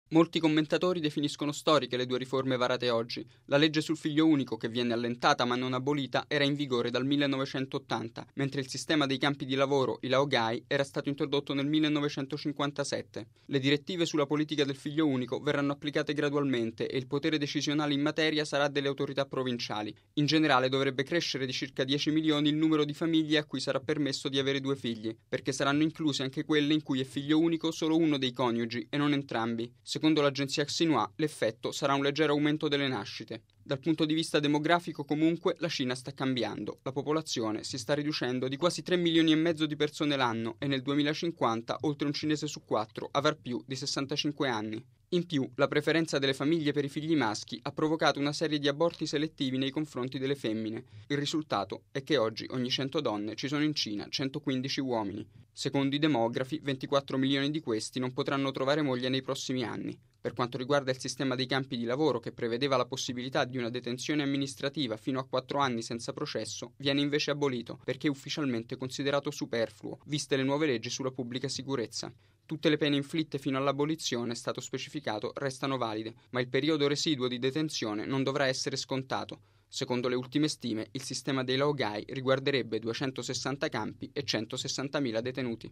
Radiogiornale del 28/12/2013 - Radio Vaticana